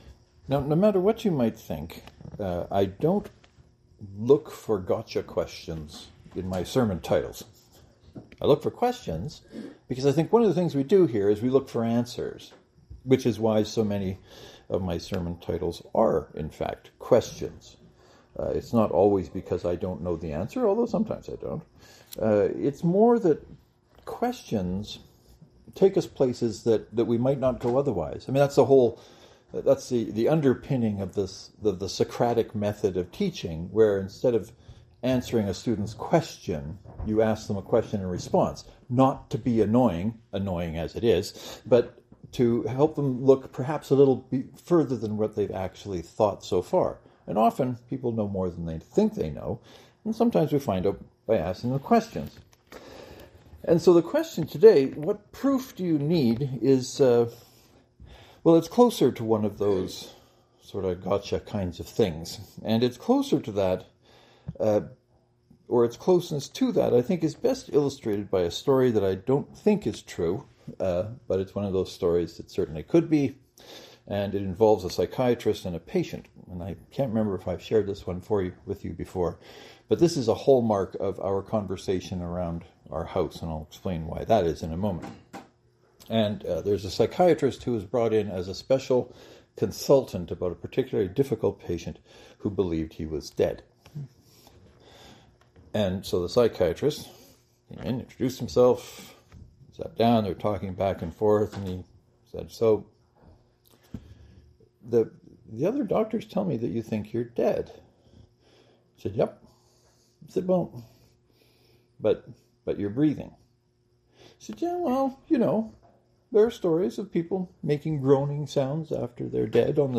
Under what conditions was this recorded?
Then you get the sermon I preached a few hours ago. I was trying to look at what we think we need proof for and what we know for which there is no proof.